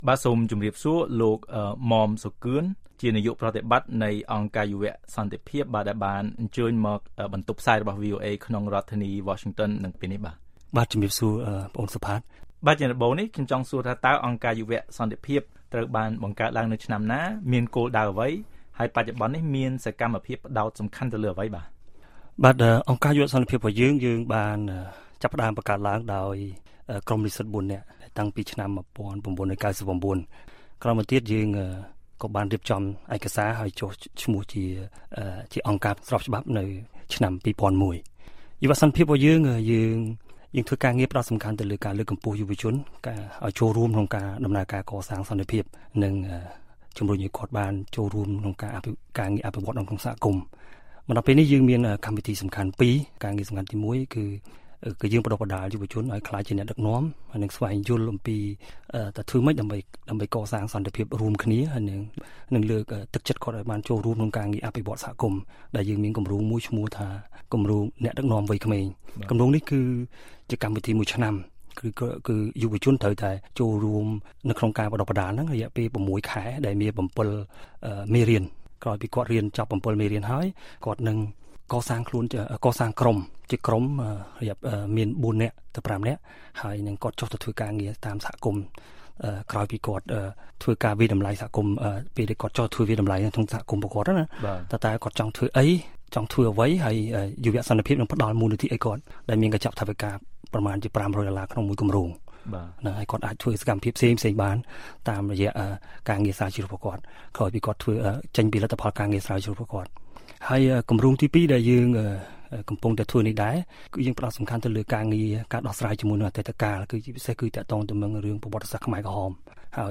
បទសម្ភាសន៍ VOA៖ យុវជនមានតួនាទីសំខាន់ក្នុងកិច្ចការ«សេសសល់‍»ក្រោយការបញ្ចប់ទៅនៃសាលាក្តីខ្មែរក្រហម